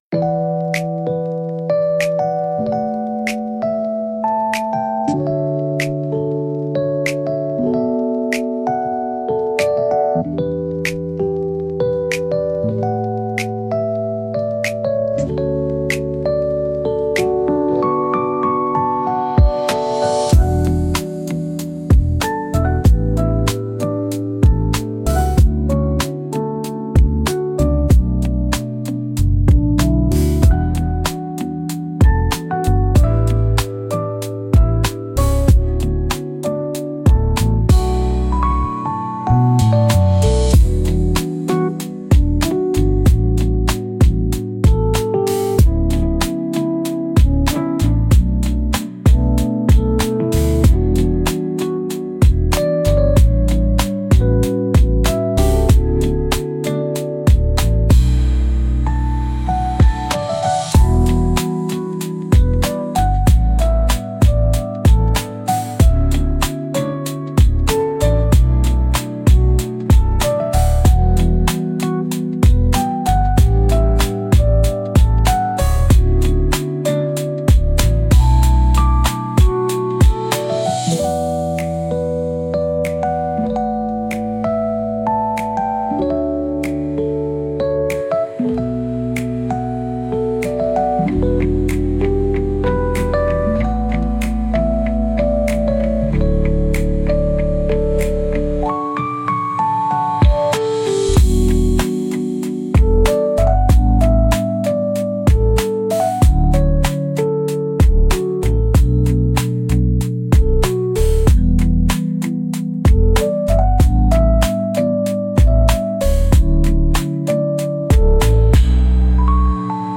悲しさを感じさせるケルト音楽です。